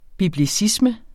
Udtale [ bibliˈsismə ]